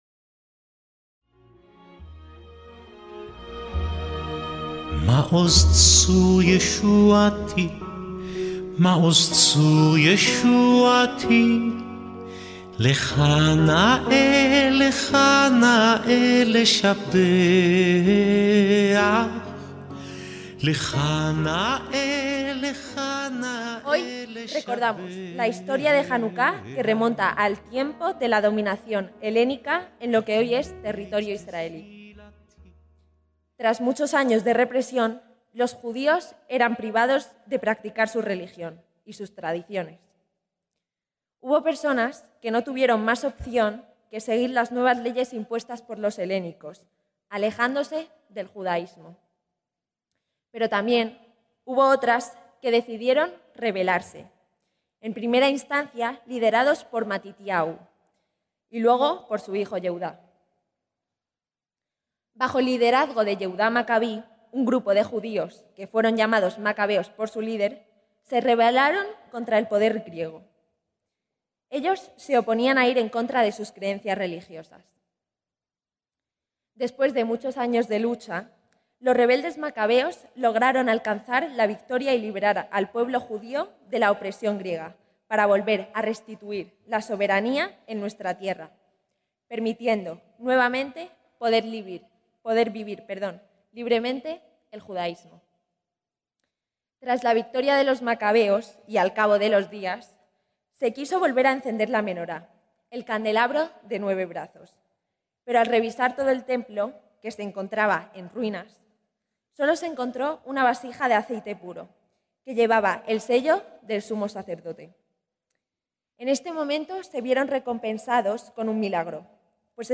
ACTOS EN DIRECTO - El Ayuntamiento de Madrid, la Comunidad Judía de Madrid y el Centro Sefarad-Israel organizaron, como cada año, la celebración de Janucá. En esta ocasión, la actividad, que tuvo lugar en la Casa de la Villa de Madrid, pudo seguirse en directo. Durante el acto tuvo lugar el tradicional encendido de velas en la januquiá, seguido de la interpretación de algunas canciones tradicionales de esta fiesta.